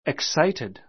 excited 中 A1 iksáitid イ ク サ イテ ド 形容詞 興奮した an excited crowd an excited crowd 興奮した群衆 get [become, grow] excited get [become, grow] excited 興奮する Don't be so excited.